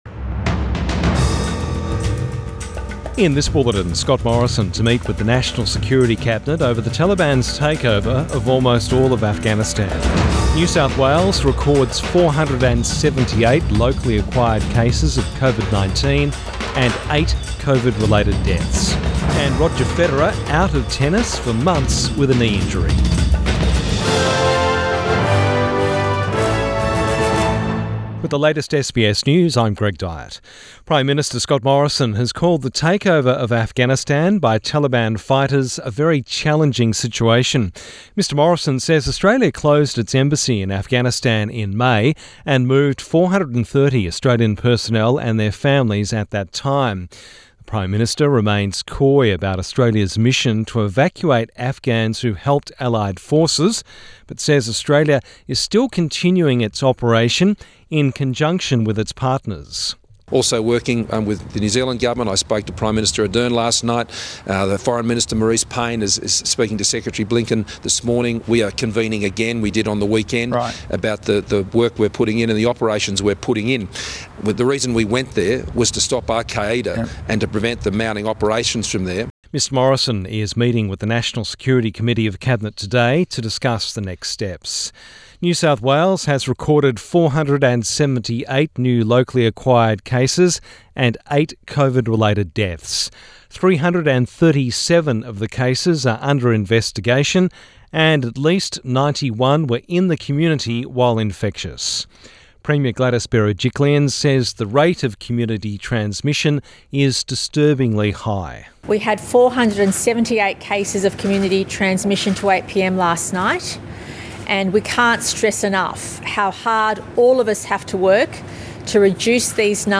Midday bulletin 16 August 2021